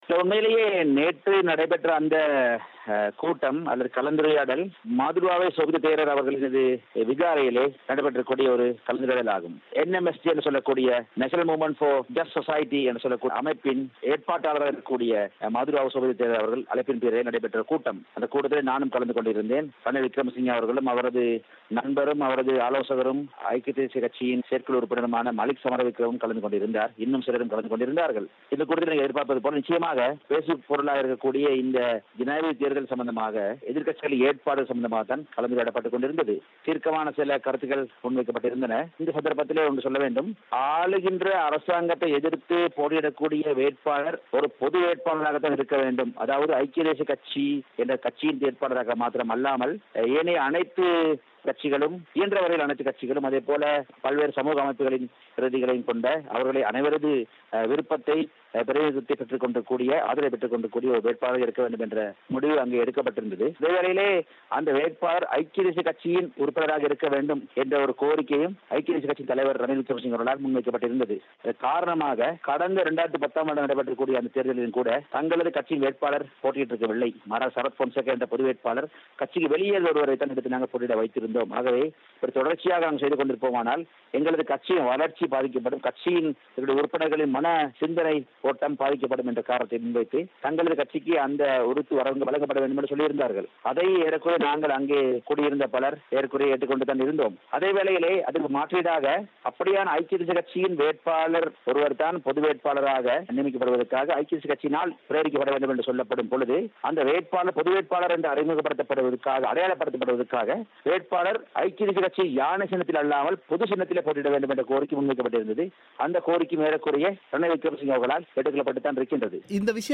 தேசிய இனப்ப்பிரச்சினைக்கான தீர்வு உட்பட, அந்த கூட்டத்தில் ஆராயப்பட்ட பலவிதமான விசயங்கள் குறித்து மனோ கணேசன் பிபிசி தமிழோசைக்கு வழங்கிய செவ்வியை நேயர்கள் இங்கு கேட்கலாம்.